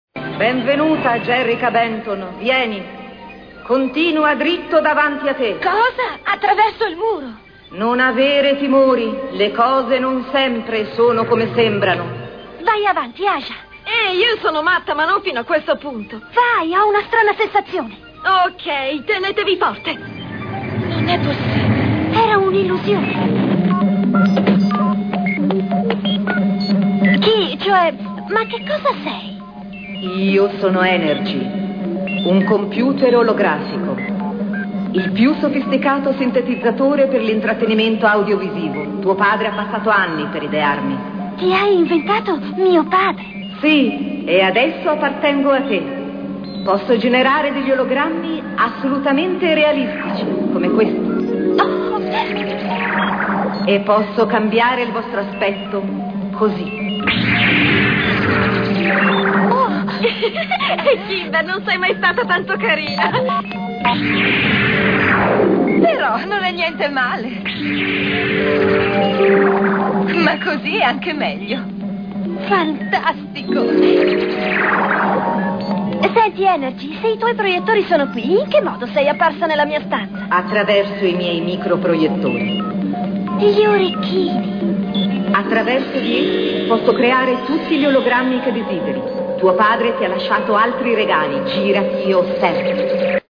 nel cartone animato "Jem", in cui doppia Energy.